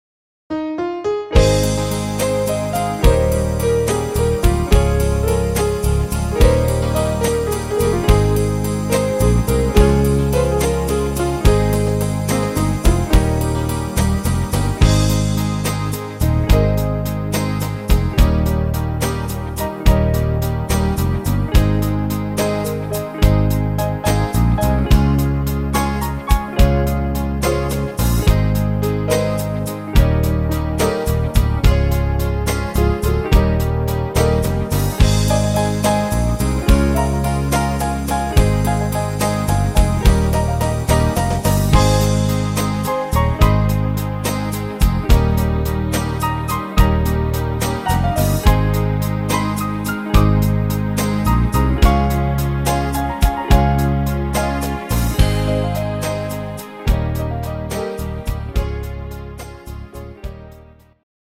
Ab-Dur